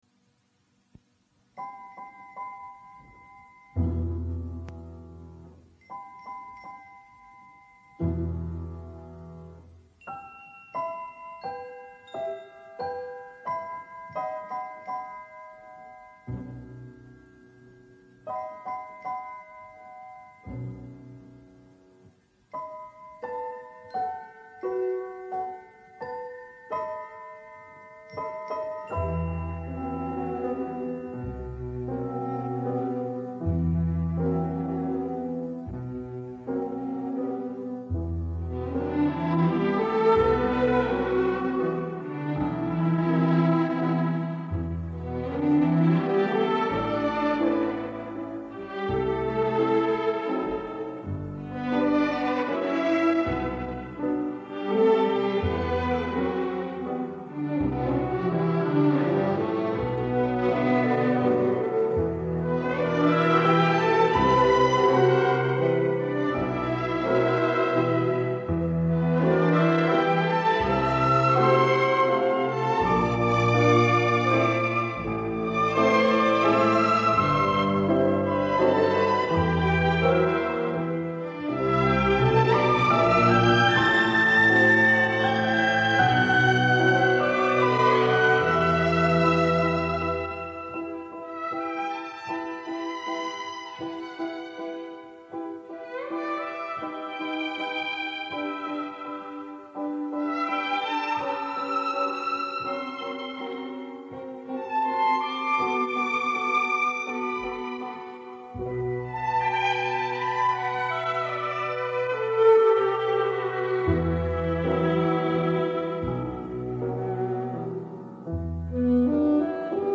[31/5/2011]小乐队演奏的轻音乐《思念》